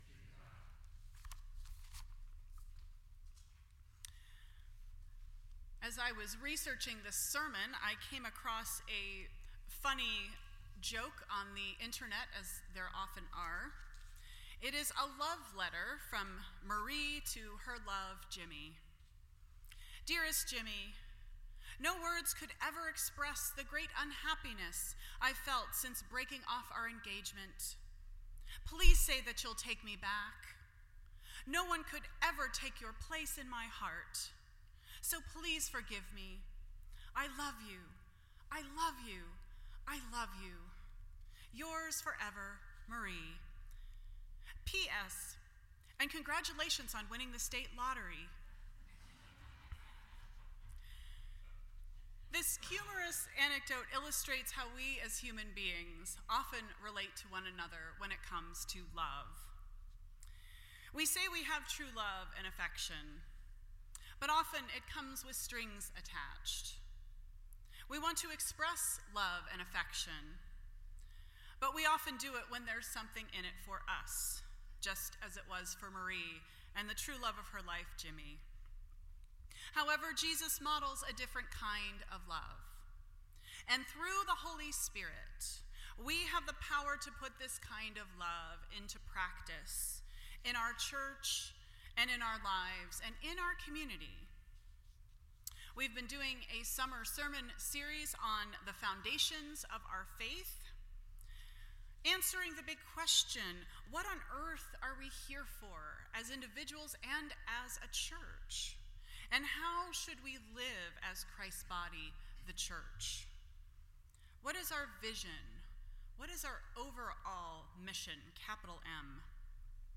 Foundations Service Type: Sunday Morning %todo_render% Share This Story